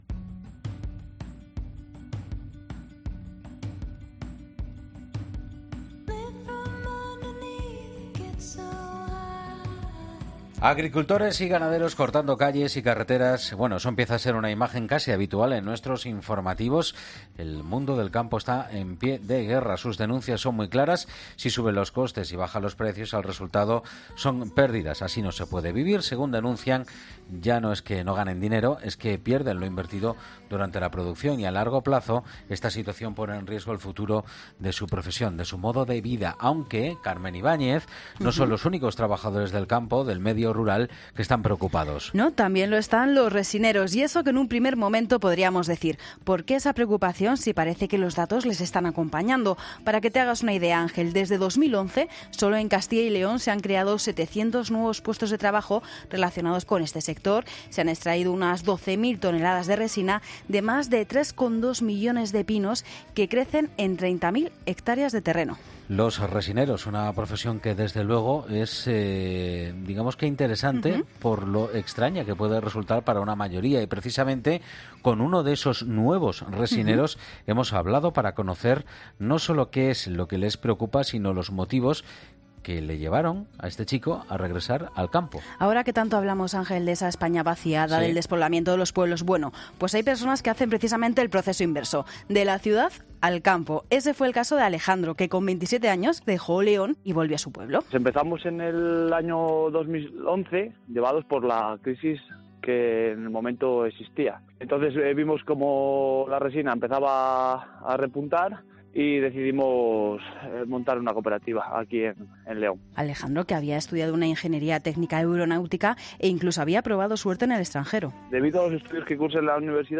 En la 'Mañana del Fin de Semana' hemos charlado con dos resineros que nos han contado cómo es su día a día y cómo ven su profesión en el futuro.